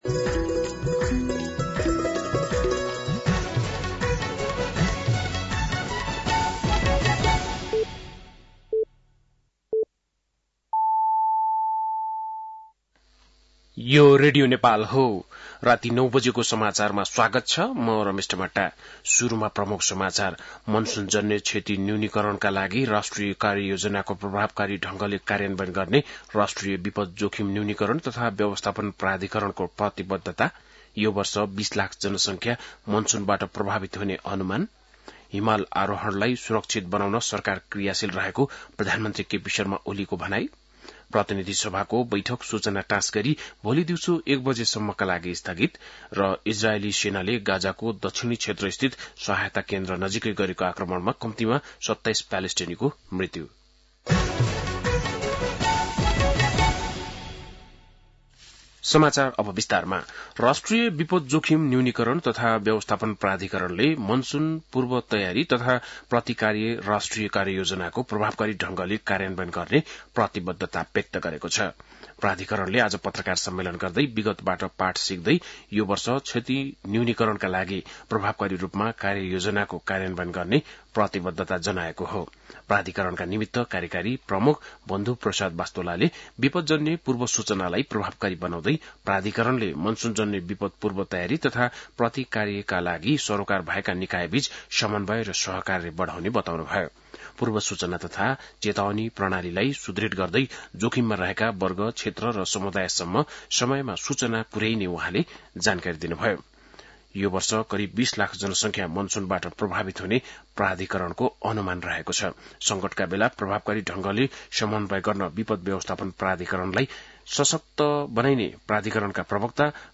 बेलुकी ९ बजेको नेपाली समाचार : २० जेठ , २०८२
9-PM-Nepali-NEWS-02-20.mp3